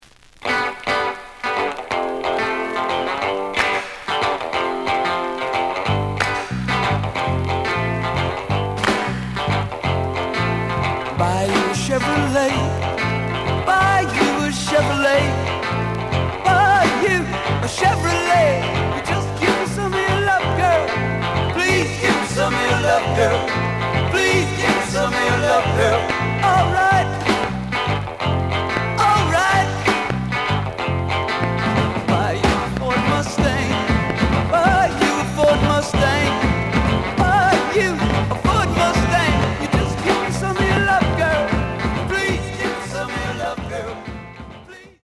The audio sample is recorded from the actual item.
●Genre: Soul, 60's Soul
Some click noise on B side due to scratches.)